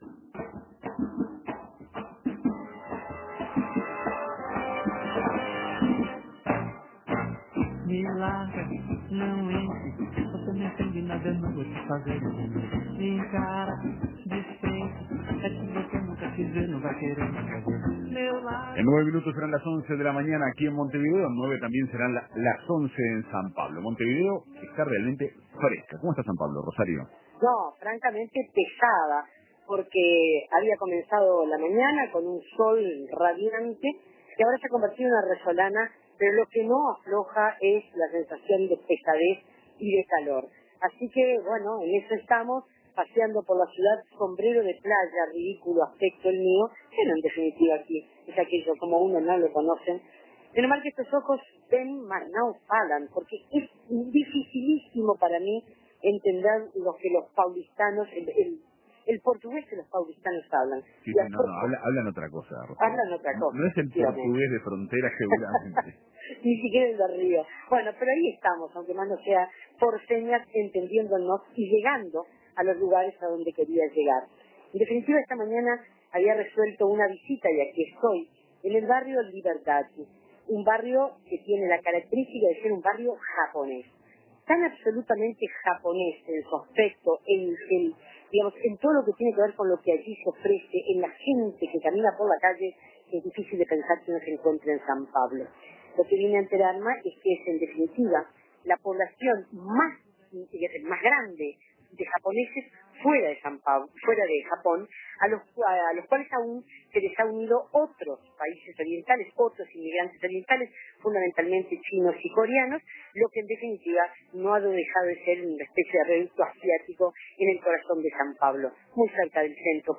"Los olhos" de la radio visita Libertad, el barrio japonés con más habitantes orientales del mundo. Móvil